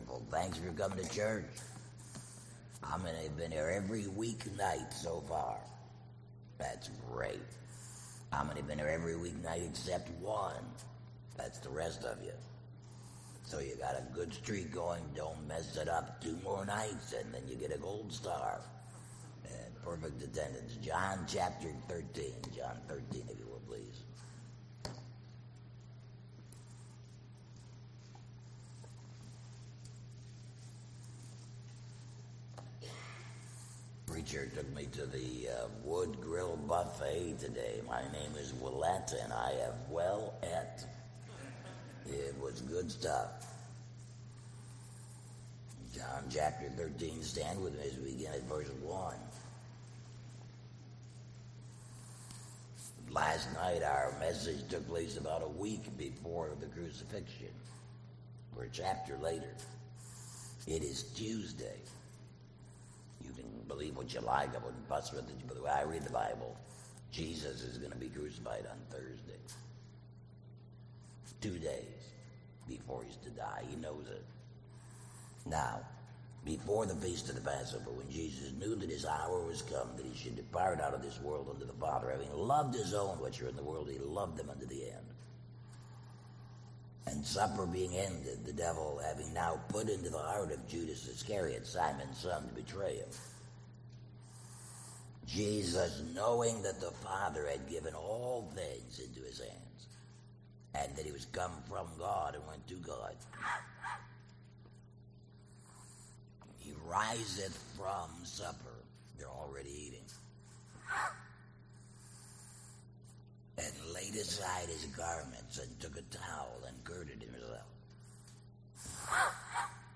Sermons Archive • Fellowship Baptist Church - Madison, Virginia